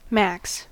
Ääntäminen
Synonyymit maxi maximum Ääntäminen : IPA : /ˈmæks/ US : IPA : [mæks] Haettu sana löytyi näillä lähdekielillä: englanti Käännös Ääninäyte Substantiivit 1. maximum {m} Määritelmät Adjektiivit maximum ; maximal .